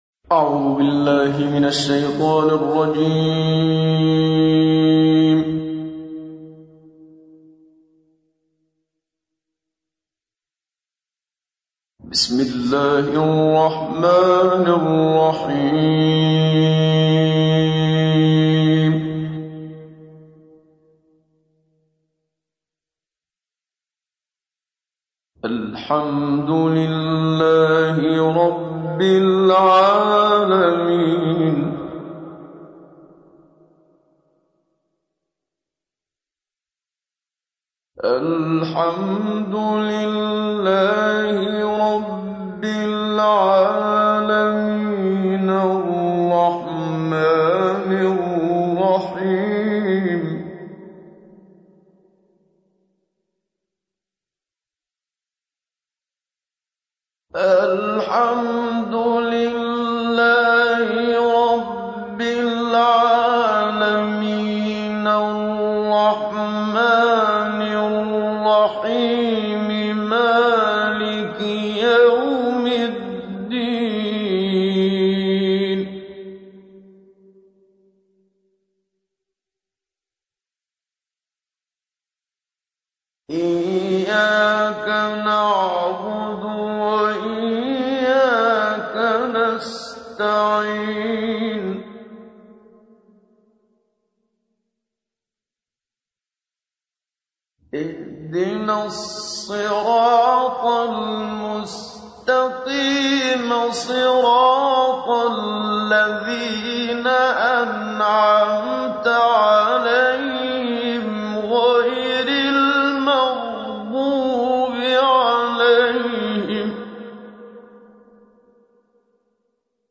تحميل : 1. سورة الفاتحة / القارئ محمد صديق المنشاوي / القرآن الكريم / موقع يا حسين